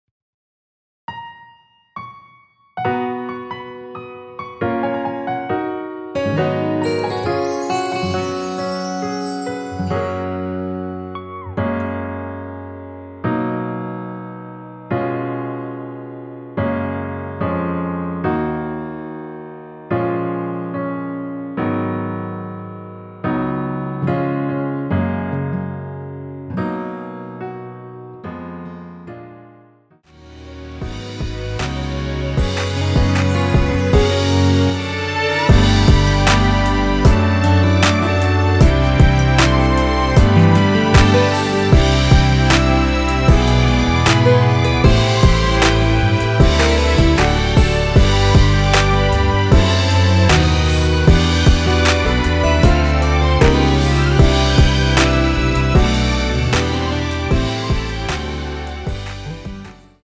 원키에서(-1)내린 MR입니다.
F#
앞부분30초, 뒷부분30초씩 편집해서 올려 드리고 있습니다.
중간에 음이 끈어지고 다시 나오는 이유는